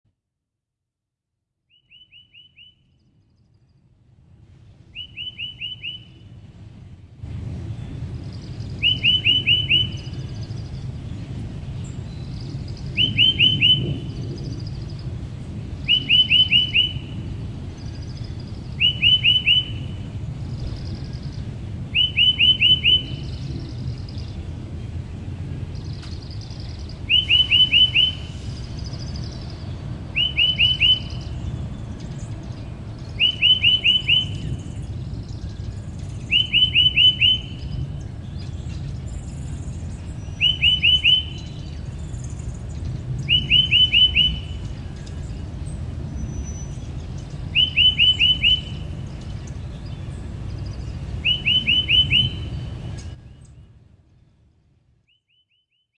Bird Bouton sonore